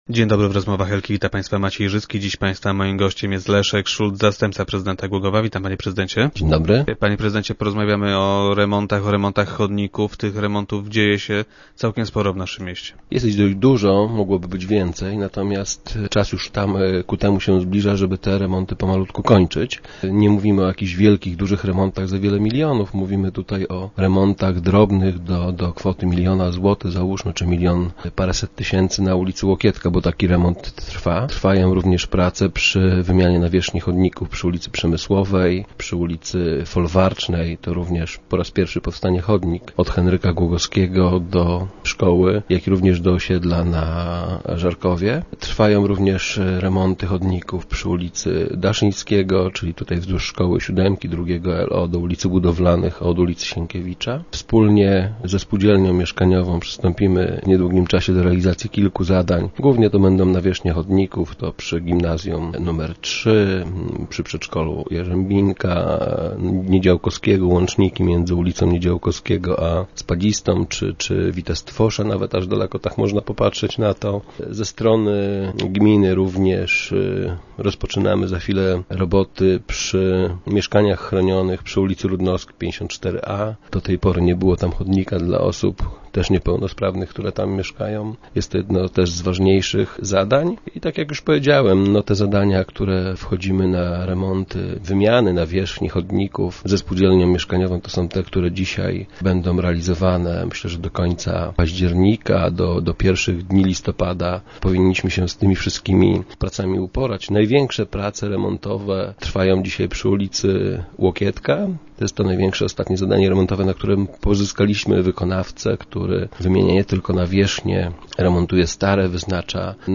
Jak twierdzi Leszek Szulc, zastępca prezydenta Głogowa, który był dziś gościem Rozmów Elki, trudno jest niestety zadowolić wszystkich.